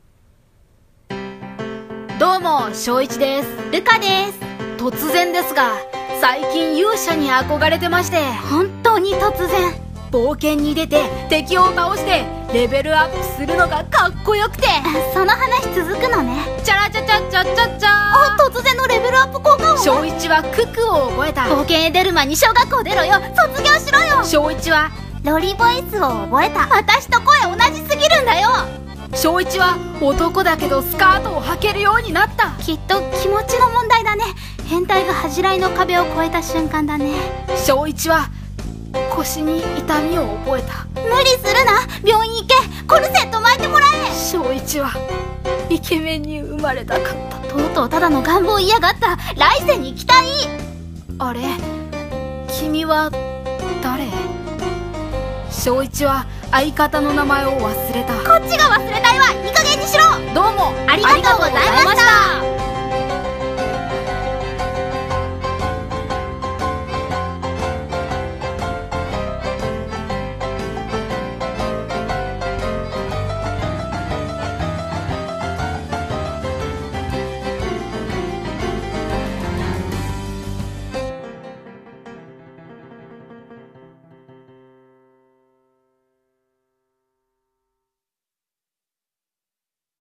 声劇【レベルアップ】※コラボ用お笑い声劇